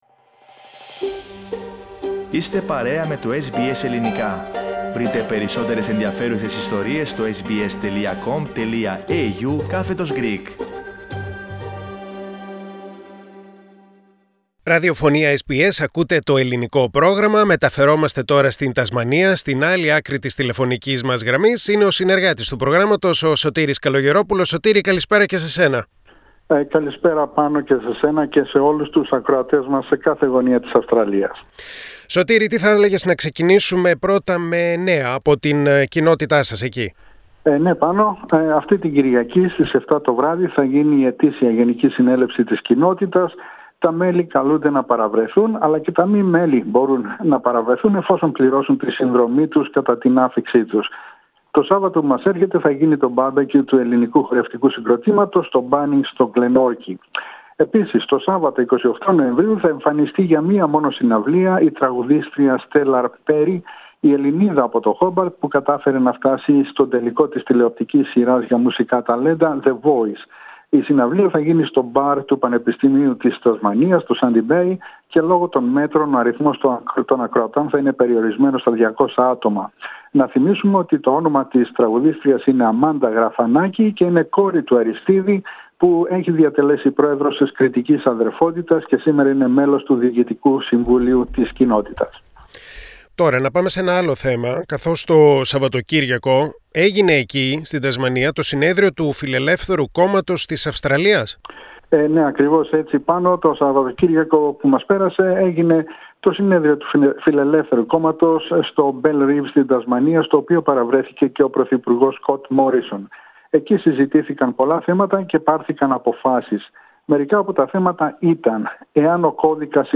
ανταπόκριση